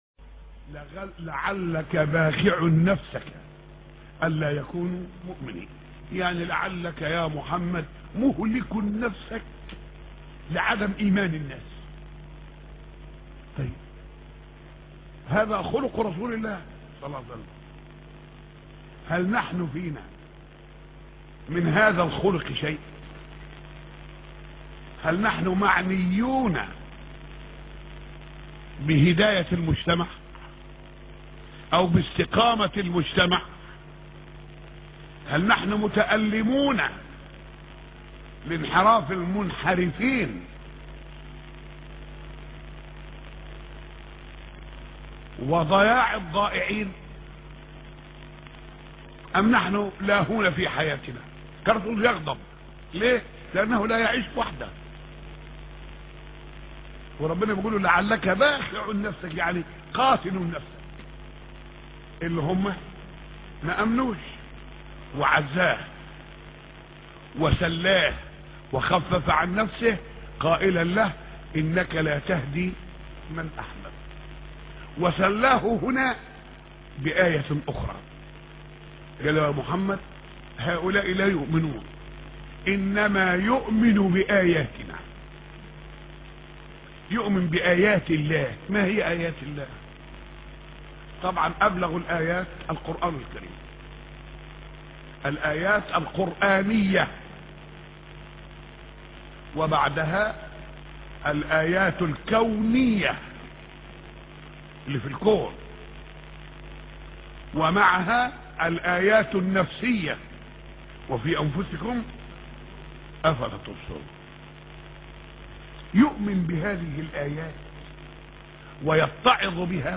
موضوع: سورة السجدة - مسجد ر.شحاته السجدة 1-5